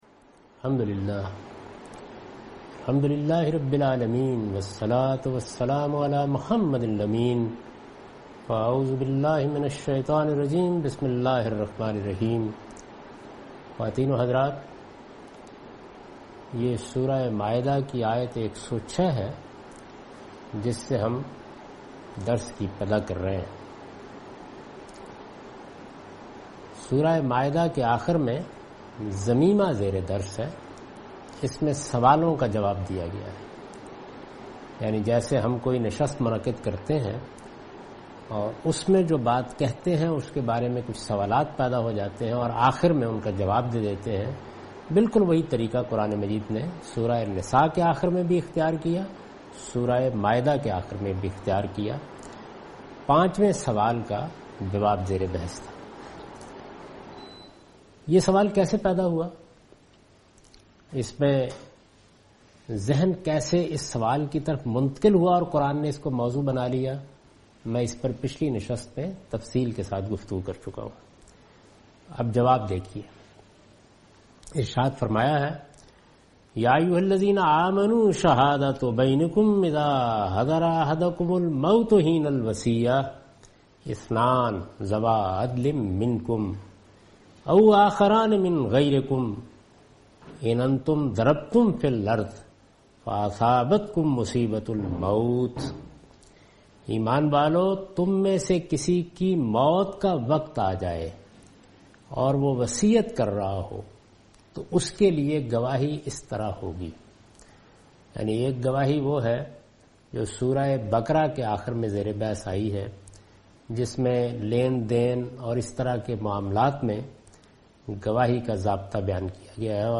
Surah Al-Maidah - A lecture of Tafseer-ul-Quran – Al-Bayan by Javed Ahmad Ghamidi. Commentary and explanation of verses 106 to 110